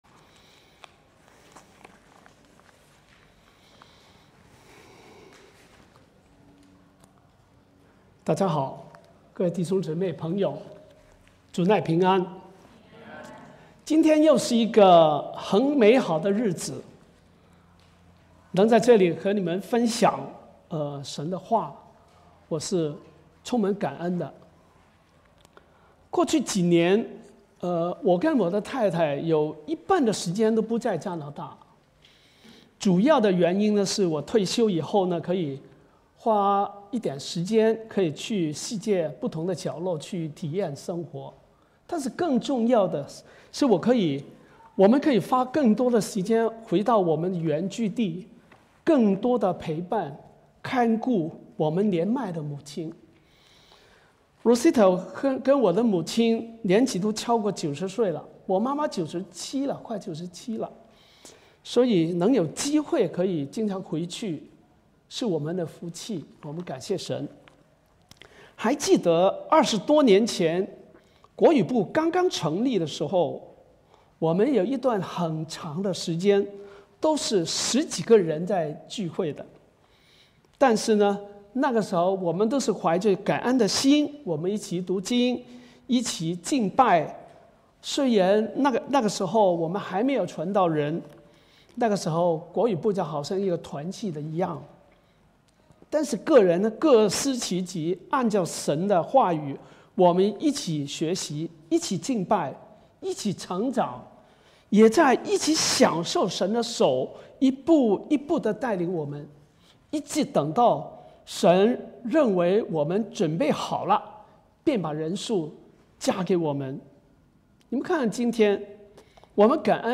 Sermons | 基督教主恩堂
Guest Speaker